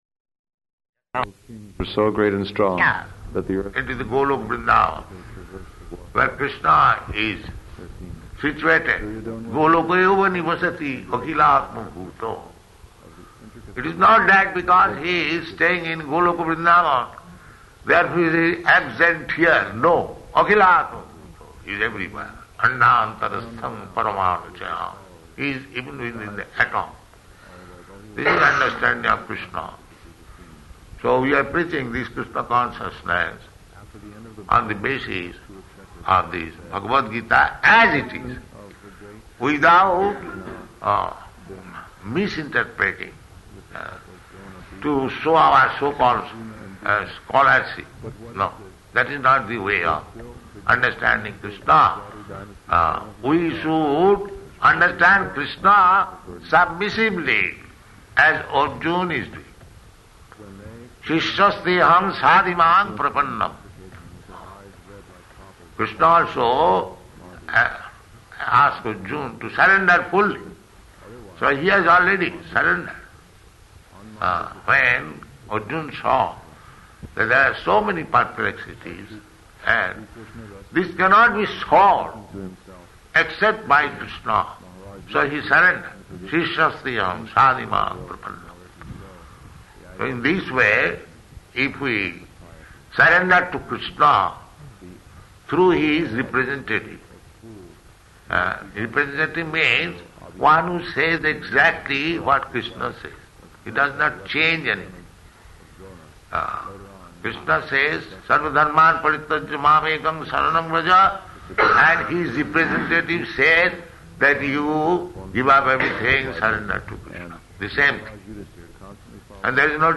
Lecture, partially recorded
Lecture, partially recorded --:-- --:-- Type: Lectures and Addresses Dated: February 12th 1974 Location: Vṛndāvana Audio file: 740212LE.VRN.mp3 Devotee: ...celebrated astronomer.